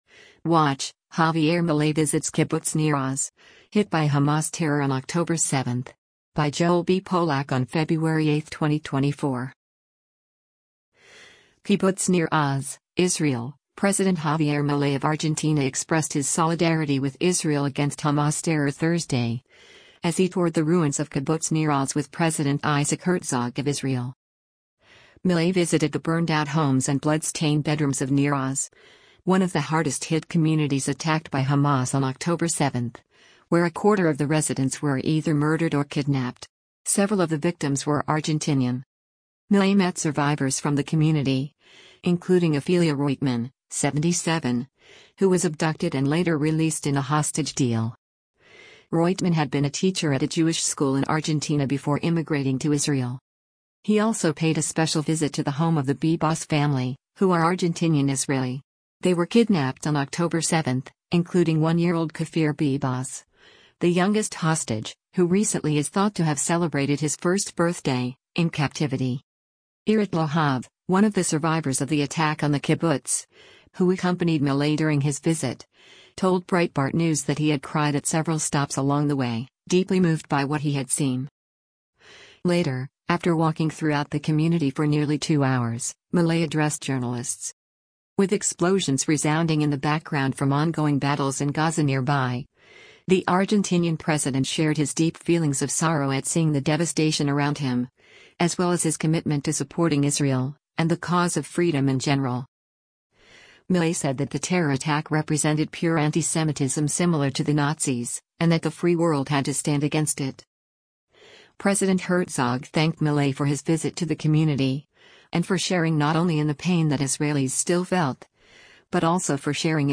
Later, after walking throughout the community for nearly two hours, Milei addressed journalists.
With explosions resounding in the background from ongoing battles in Gaza nearby, the Argentinian president shared his deep feelings of sorrow at seeing the devastation around him — as well as his commitment to supporting Israel, and the cause of freedom in general.